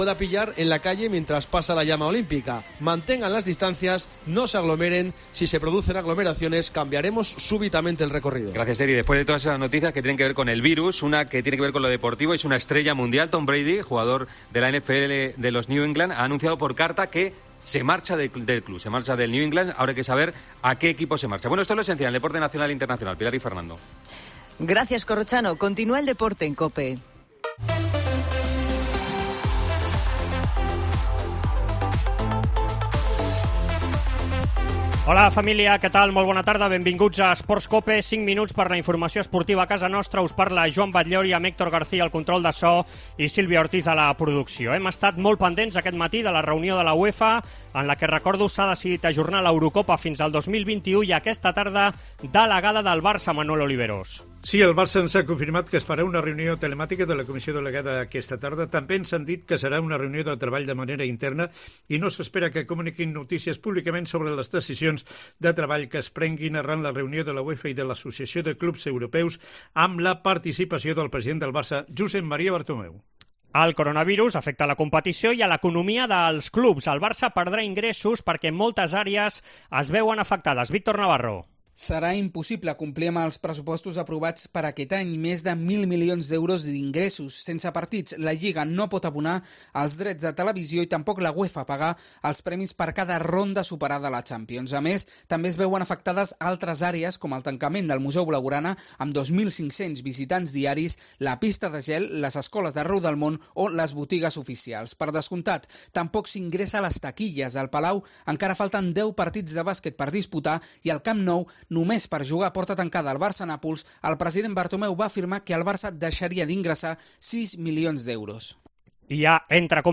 tot l'equip des de casa